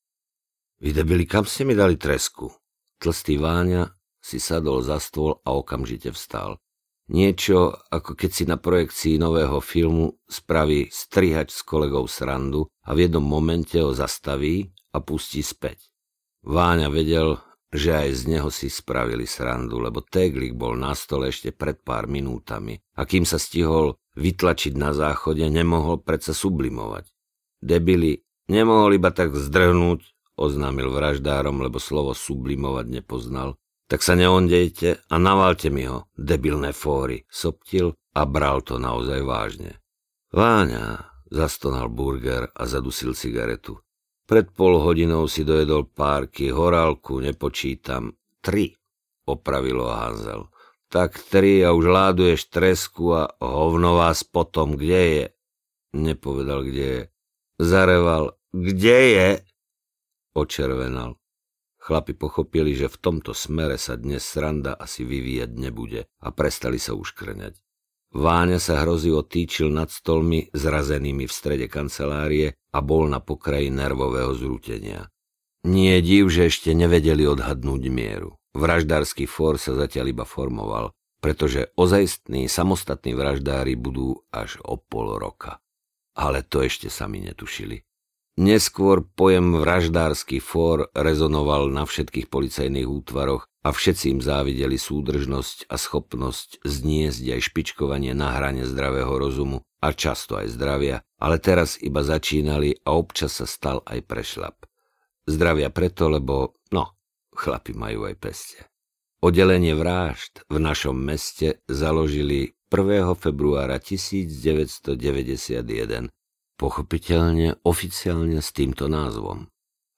Mucha audiokniha
Ukázka z knihy